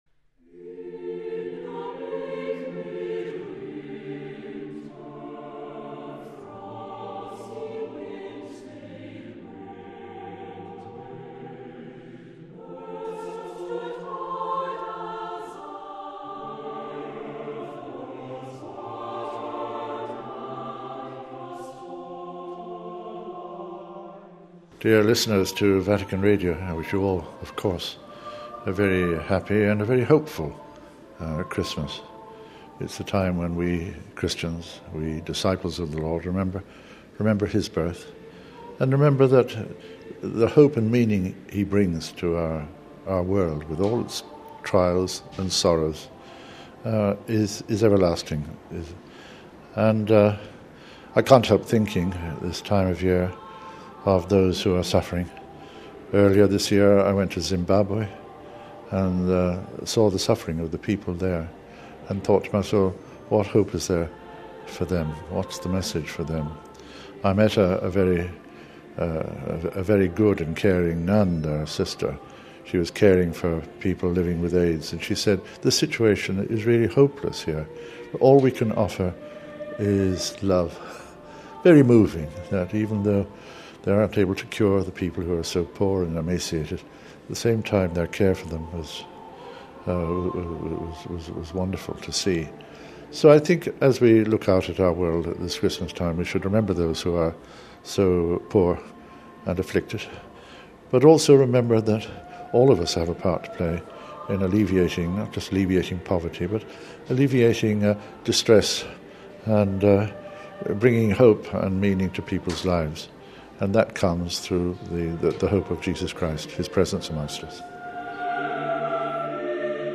(28 Dec 08 - RV) Each year at Christmas, we invite Church leaders from around the world to share with us a message to mark the season. This evening, we bring you greetings from the Archbishop of Westminster England, Cardinal Cormac Murphy O’Connor: